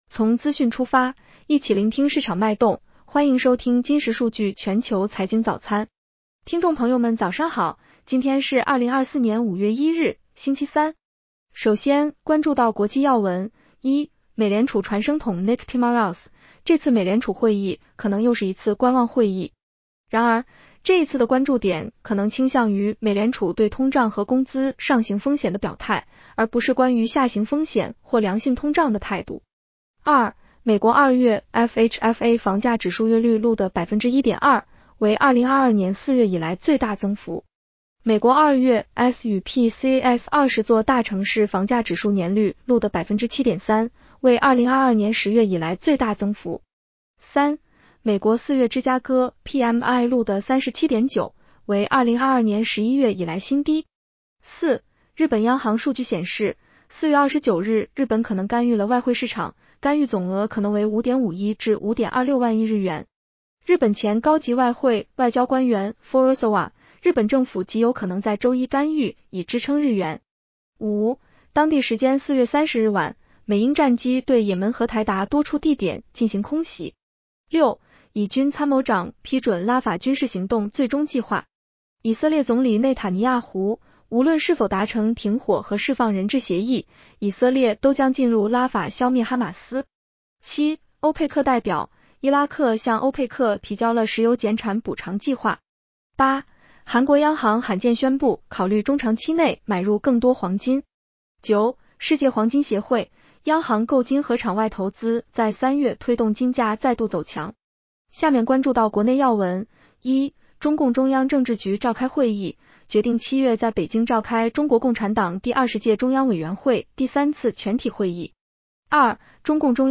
女聲普通話版